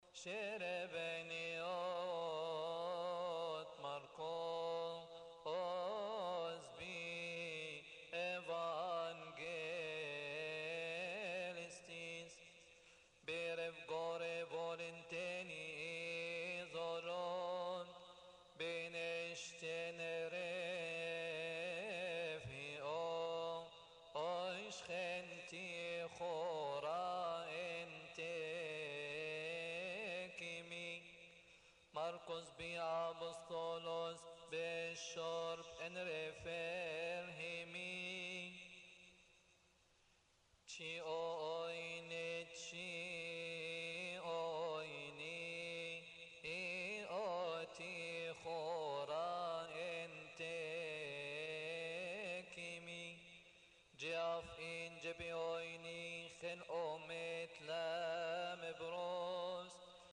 المصدر: المرتل